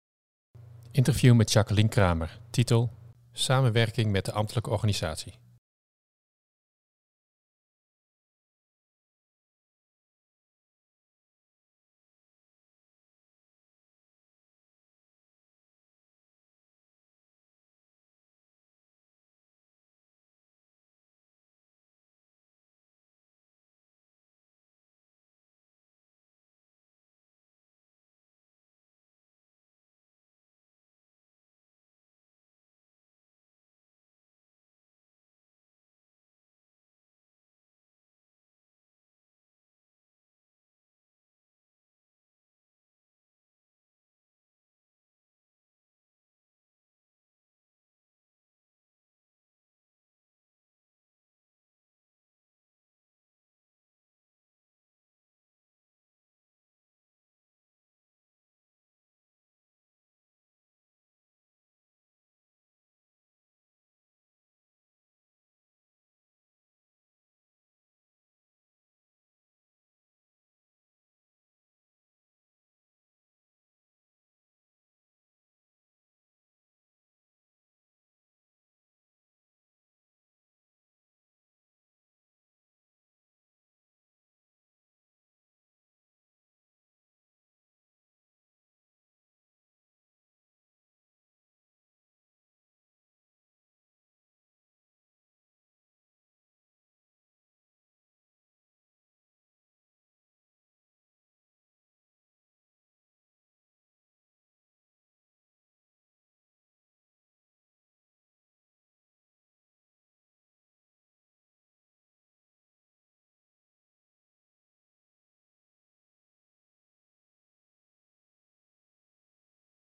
Interview met Jacqueline Cramer